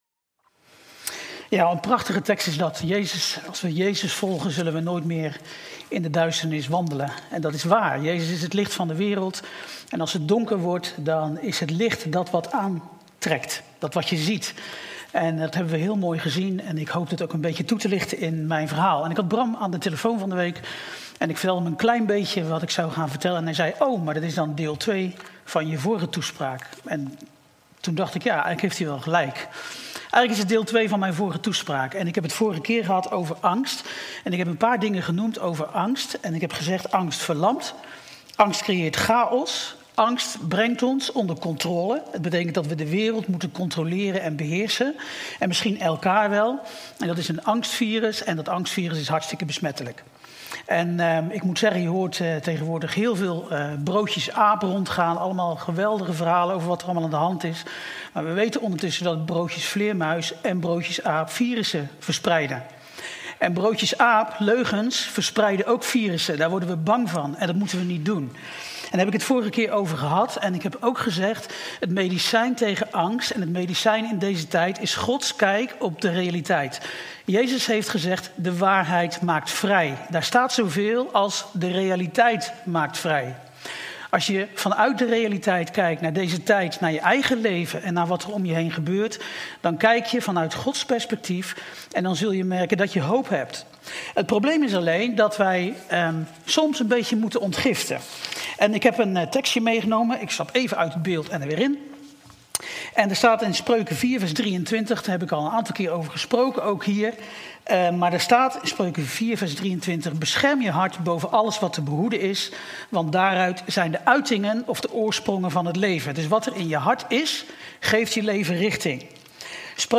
Toespraak 17 mei: Het wordt licht als het donker wordt.